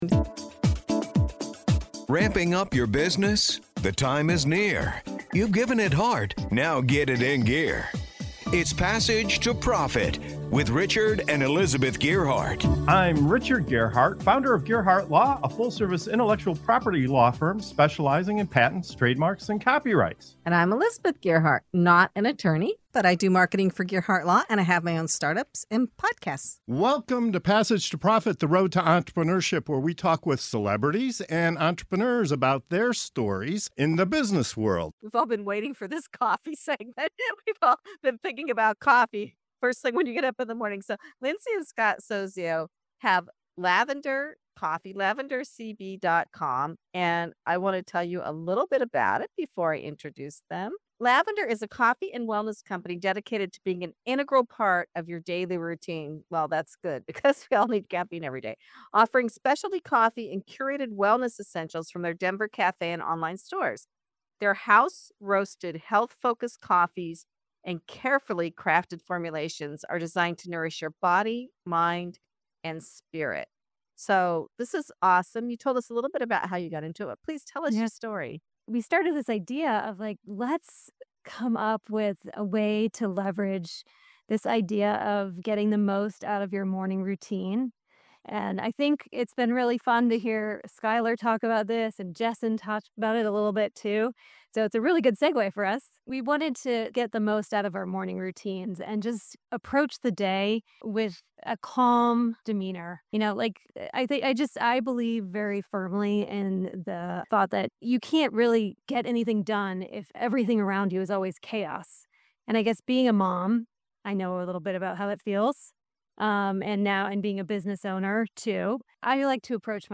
Grab a cup and join us for this inspiring conversation!